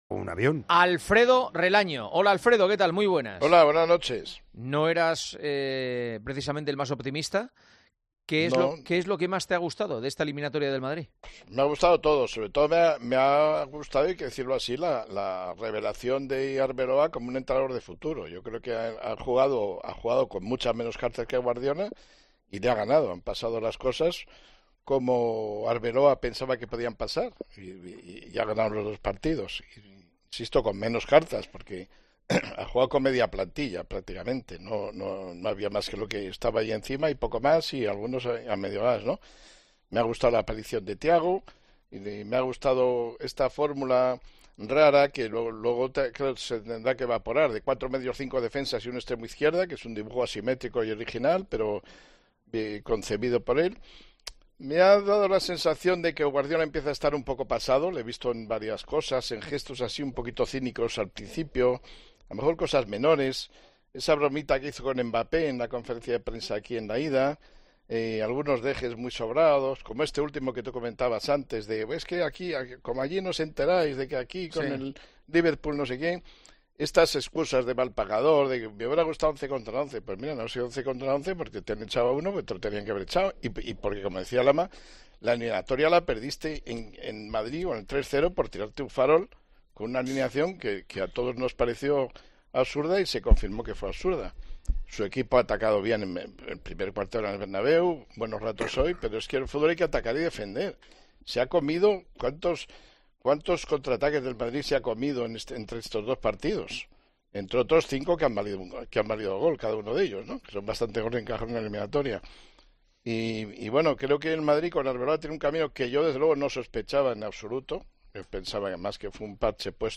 Juanma Castaño analiza a Arbeloa como entrenador revelación y crítica a Guardiola con Paco González y Alfredo Relaño
Esta clasificación ha desatado un intenso debate en 'El Partidazo de COPE', donde las figuras de Álvaro Arbeloa y Pep Guardiola han sido analizadas al detalle por Juanma Castaño, Paco González y Alfredo Relaño.